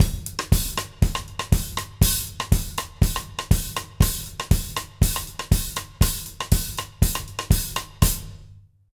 120ZOUK 01-L.wav